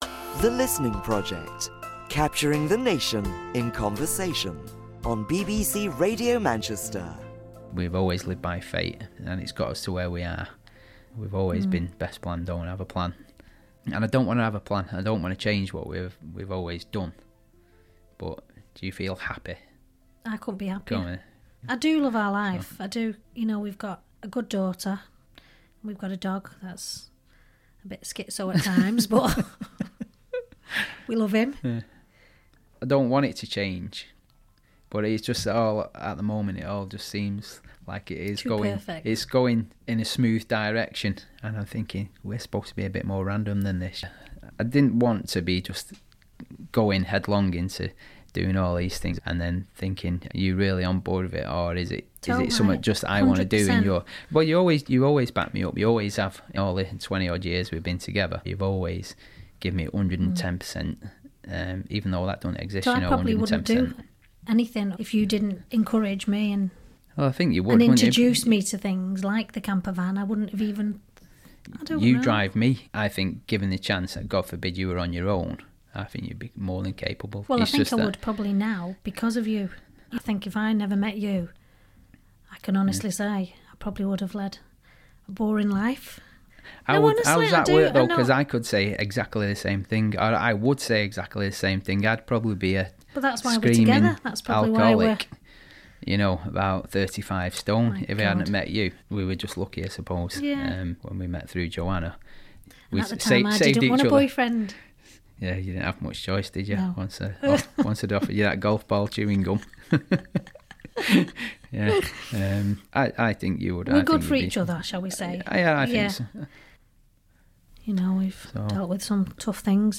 Today is the start The Listening Project here on BBC Radio Manchester. It's your chance to eavesdrop on some very special conversations.